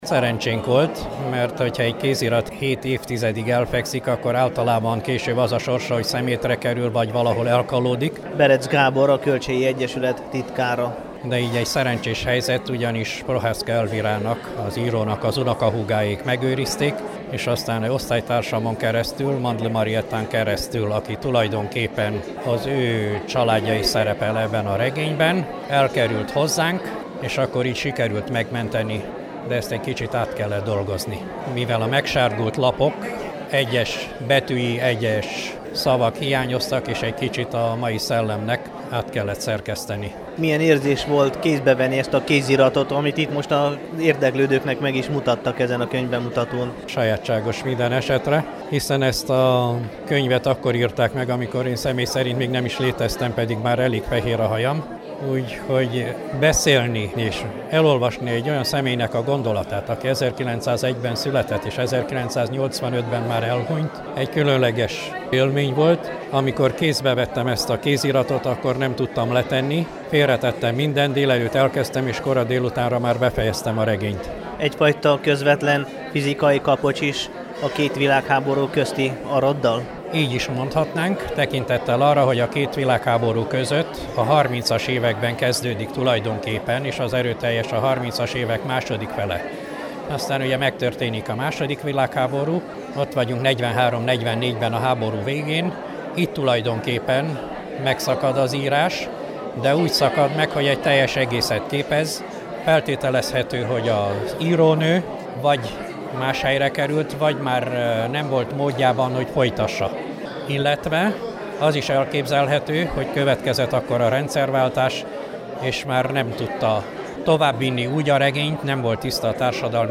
Matyas_Anna_Rovidaru_Konyvbemutato.mp3